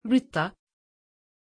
Aussprache von Britta
pronunciation-britta-tr.mp3